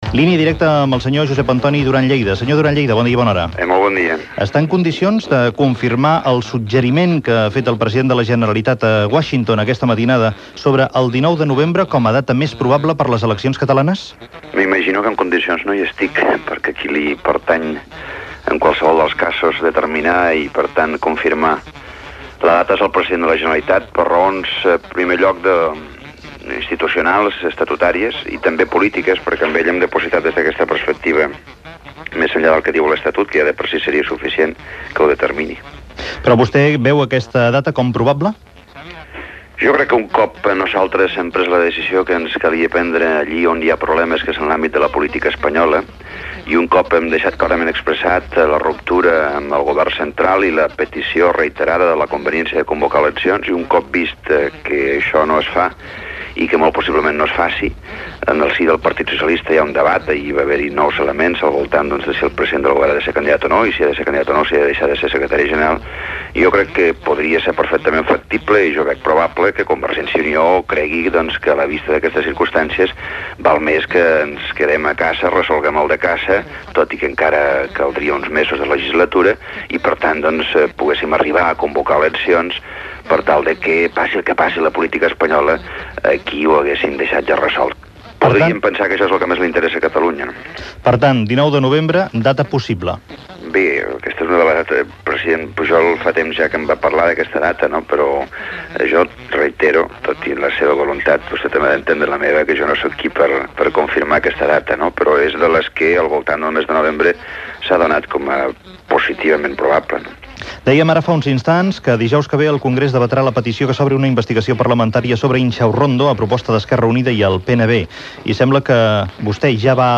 Fragment d'una entrevista amb el polític d'Unió Democràtica de Catalunya, Josep Antoni Duran i Lleida, sobre la possibilitat d'eleccions catalanes el 19 de novembre i a la comissió parlamentària sobre Intxaurrondo.
Info-entreteniment